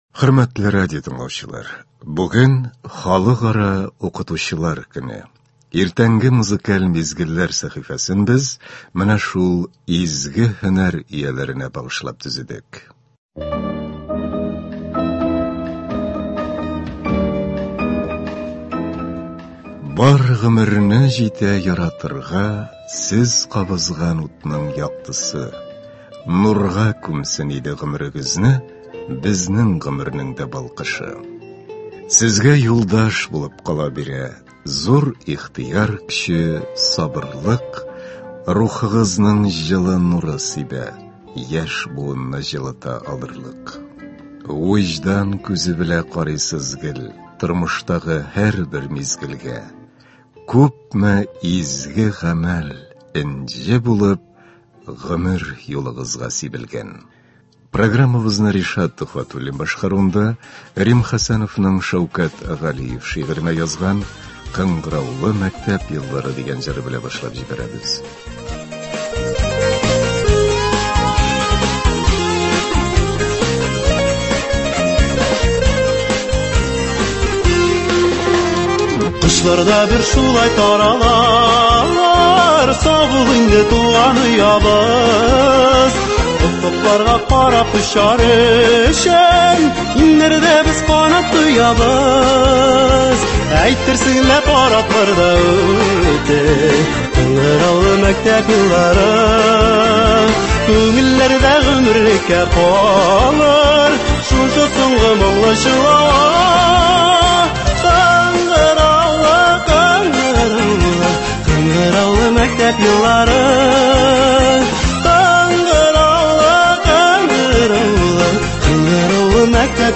Концерт укытучылар көненә багышланган.